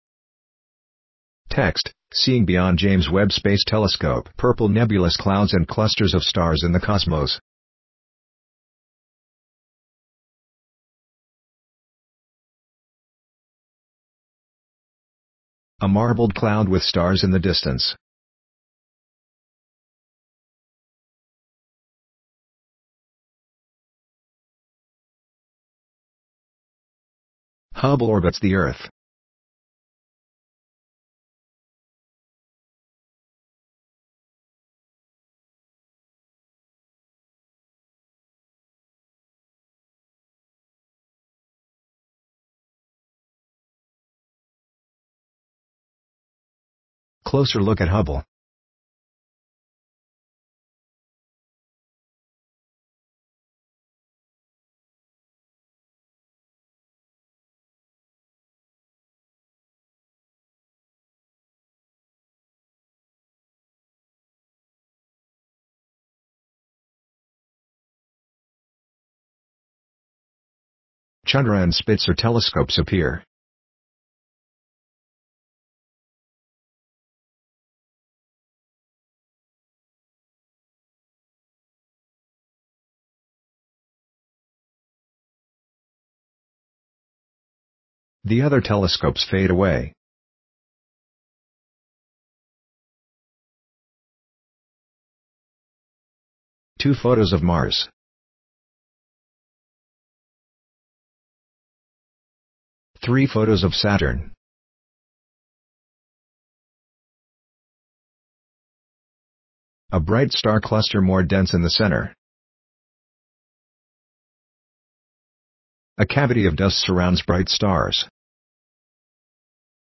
Audio Description.mp3